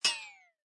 ting.3.ogg